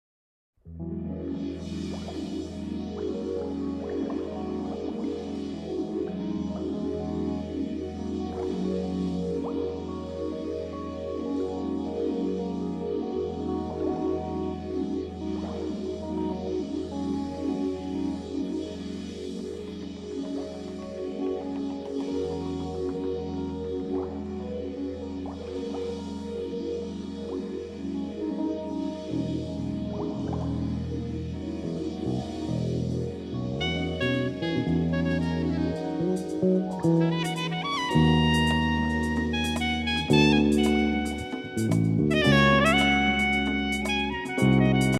どこか異国情緒漂うスタイリッシュなメロディに乗せて、軽快に弾むリズムが心地良い